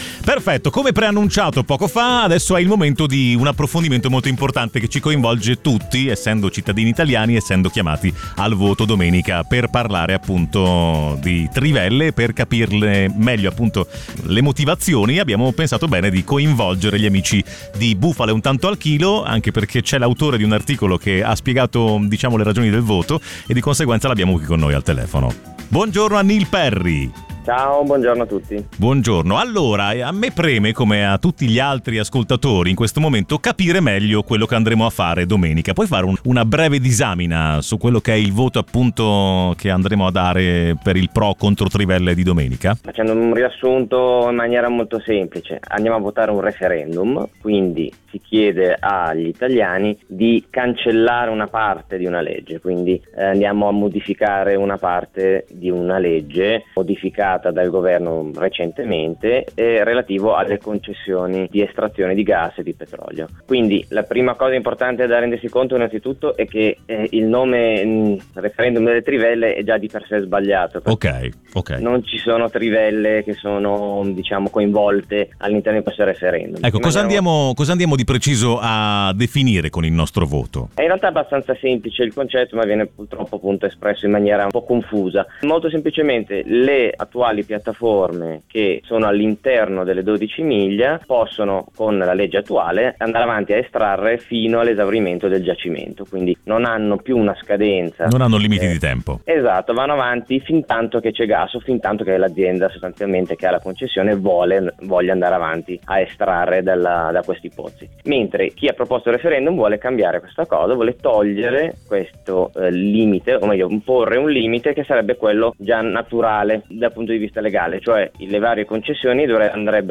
Anche nel consueto appuntamento del giovedì con la redazione di Bufale Un Tanto Al Chilo, abbiamo voluto affrontare il tema del referendum del 17 aprile, per capire ancora meglio cosa gli italiani andranno a votare.